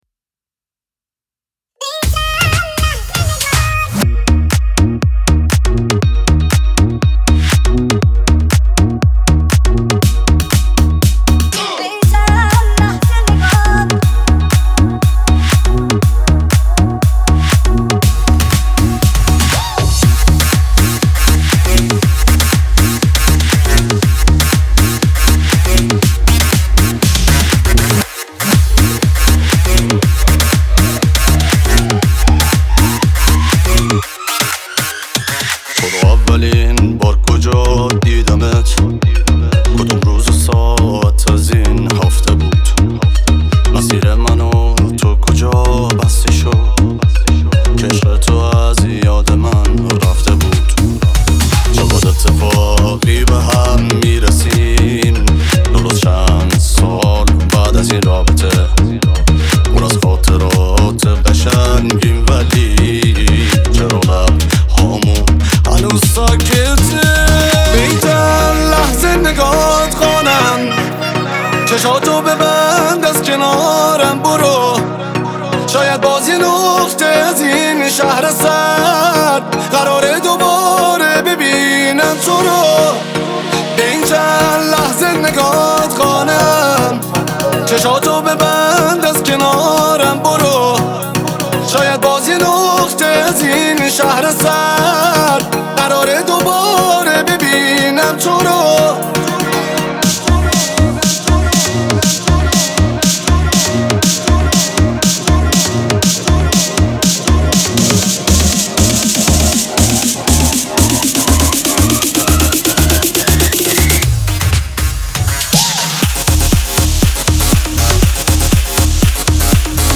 آهنگ ریمیکس شاد